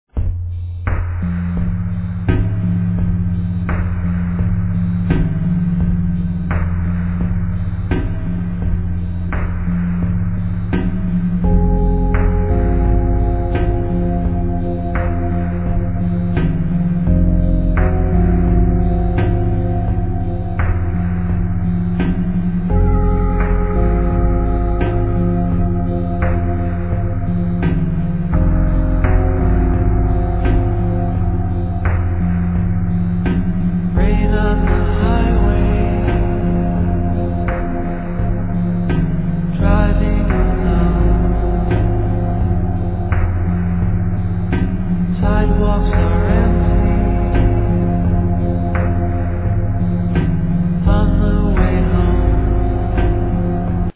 Synthesizer, Voice